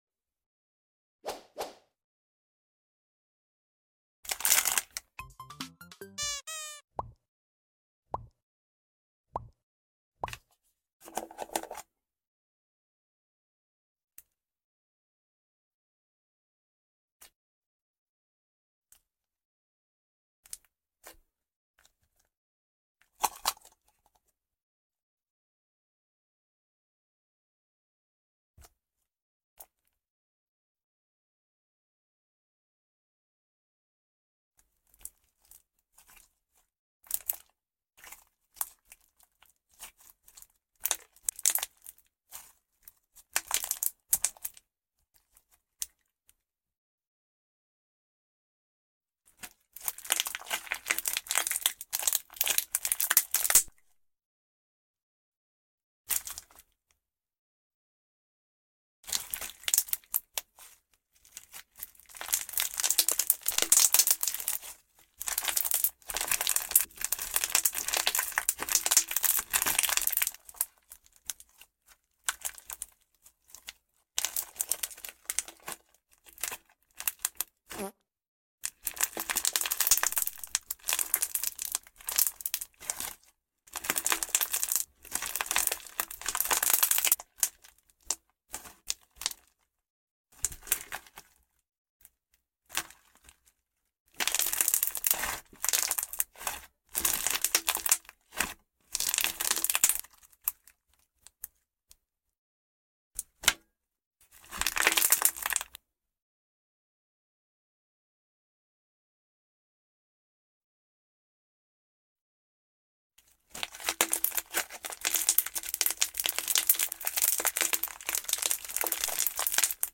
Slime ASMR. Fruity Loops Cereal sound effects free download
This is the crunchiest slime I think I’ve ever had.